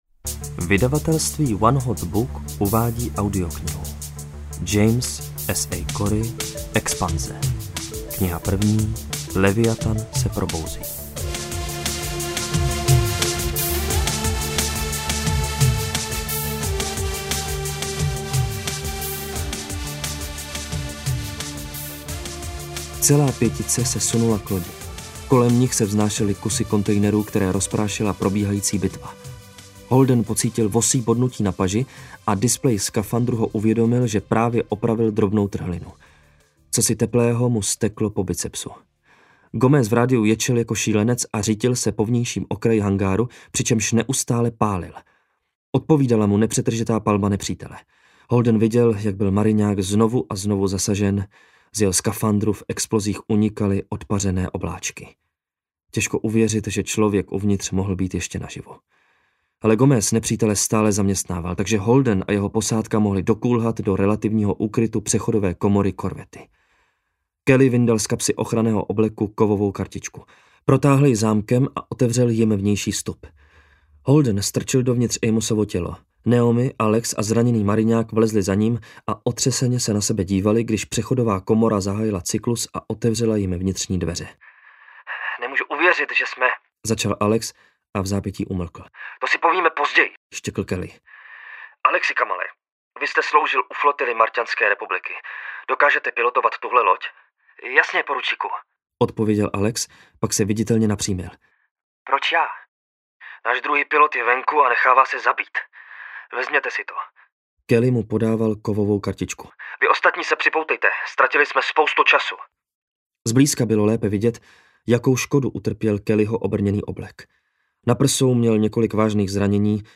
Leviatan se probouzí audiokniha
Ukázka z knihy